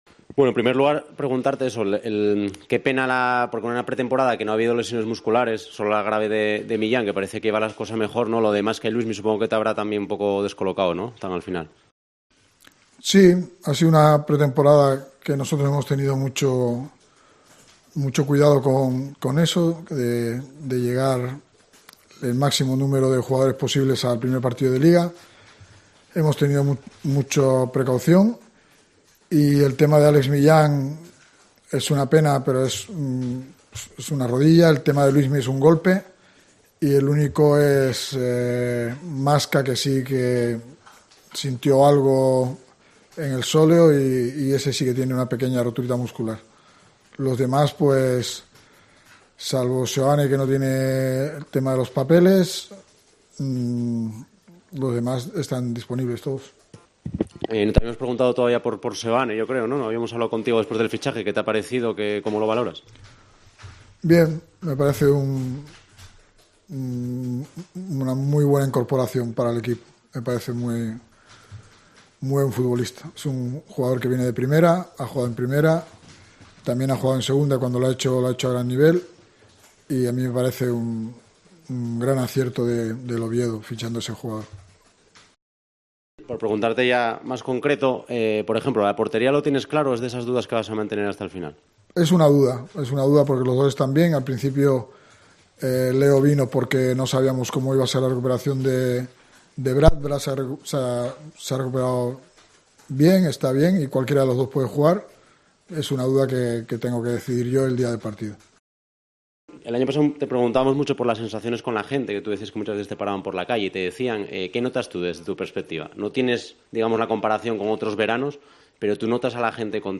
Álvaro Cervera compareció ante los medios de comunicación en sala de prensa antes del debut liguero del Real Oviedo este lunes (21:30 horas) en Tenerife.